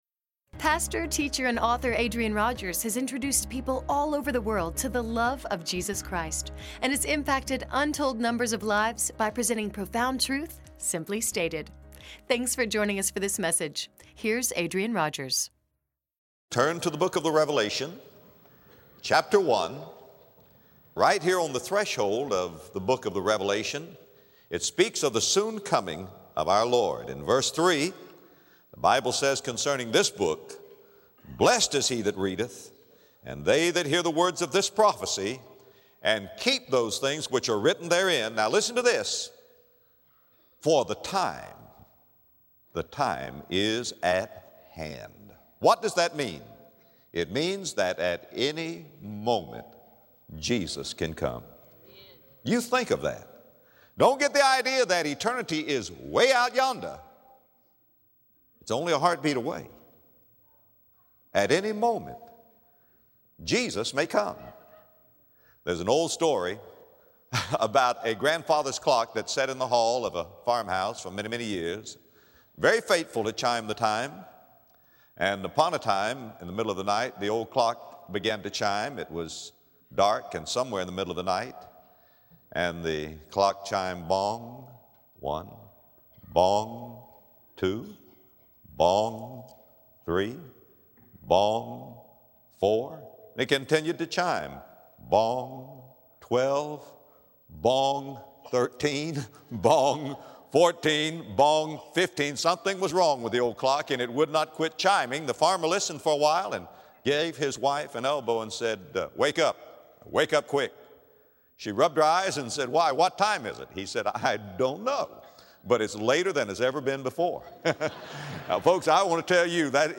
In this message, Adrian Rogers gives insight regarding the soon coming of our Lord, Jesus Christ.